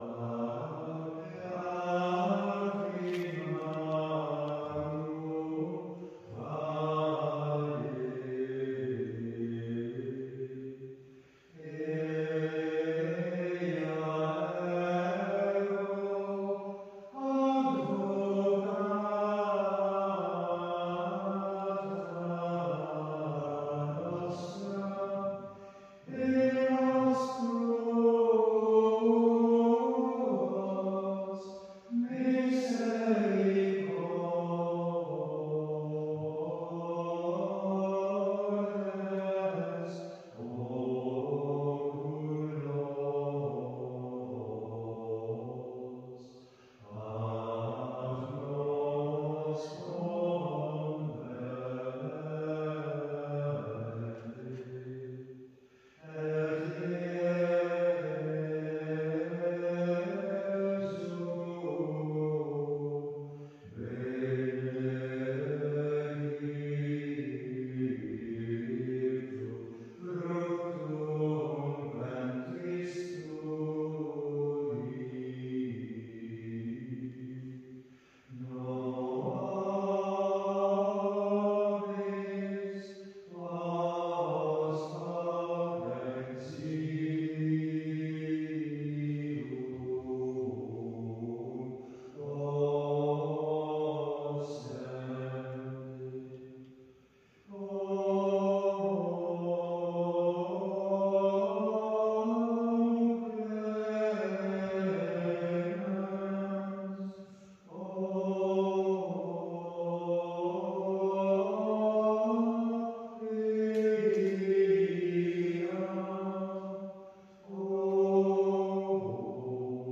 sung by the monks of Caldey Island